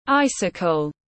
Cột băng tuyết tiếng anh gọi là icicle, phiên âm tiếng anh đọc là /ˈaɪ.sɪ.kəl/
Icicle /ˈaɪ.sɪ.kəl/